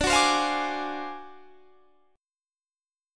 D 7th Sharp 9th Flat 5th